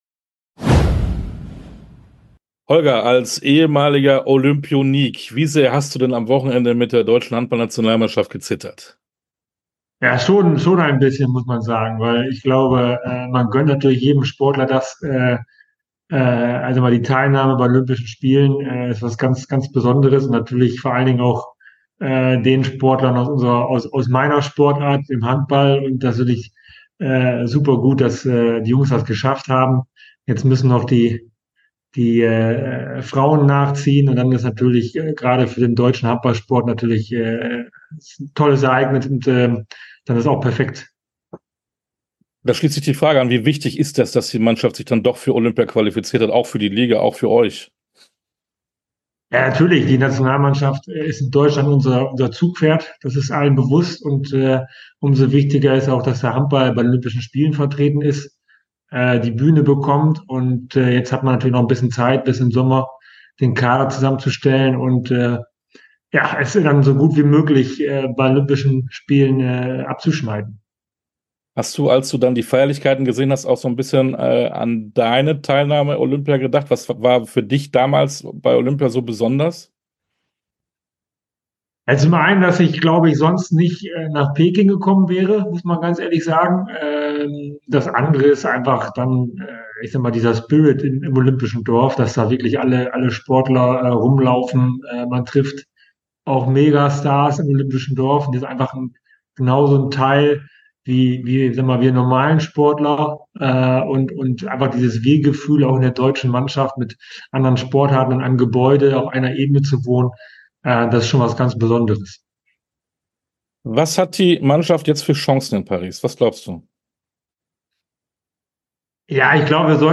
Sportstunde - Interview komplett Holger Glandorf, Handball Geschäftsführer SG Flensburg ~ Sportstunde - Interviews in voller Länge Podcast